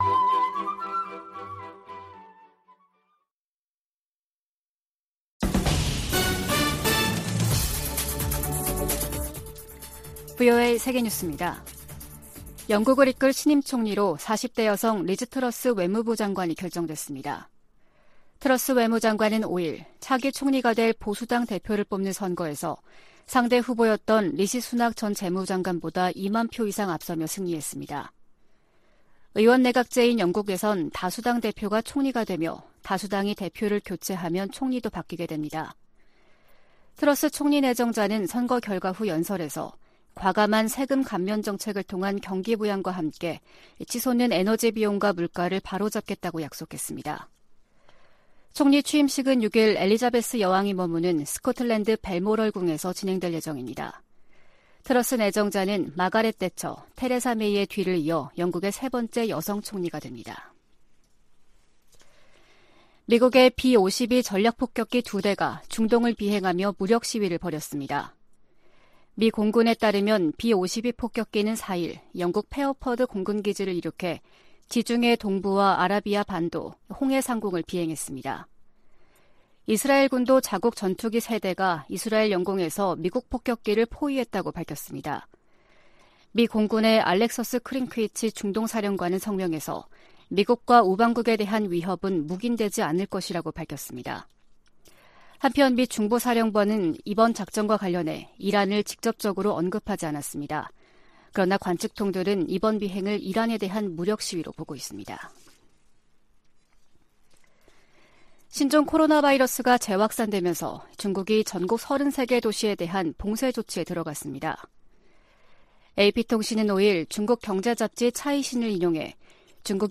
VOA 한국어 아침 뉴스 프로그램 '워싱턴 뉴스 광장' 2022년 9월 6일 방송입니다. 미국과 한국, 일본 북핵 수석대표가 일본에서 회동하고 북한 비핵화 문제 등을 논의합니다. 미국의 한반도 전문가들은 지난 1일 하와이에서 열린 미한일 안보수장 회동에 관해 3국 공조를 위한 노력이 최고위급 수준에서 이뤄지고 있는 것이라고 평가했습니다. 주한미군 고고도미사일방어체계(THAAD·사드) 기지 정상화를 위한 장비와 물품 반입이 본격화하는 양상입니다.